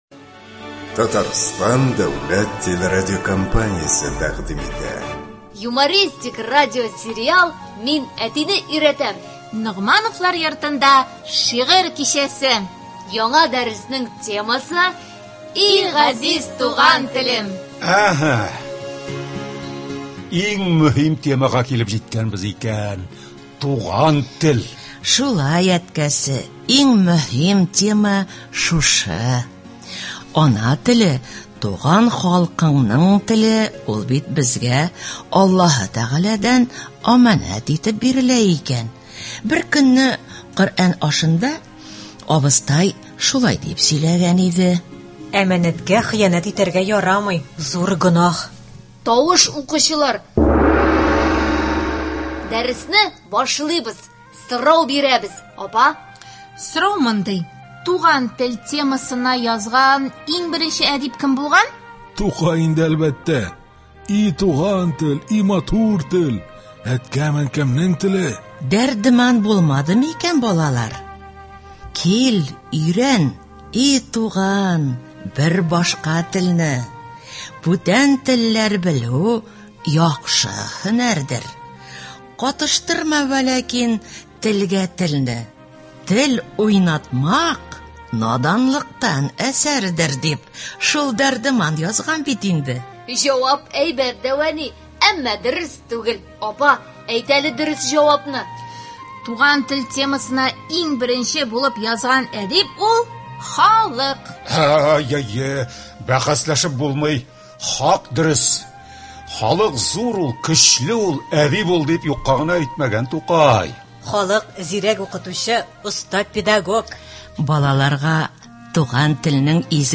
Радиосериал.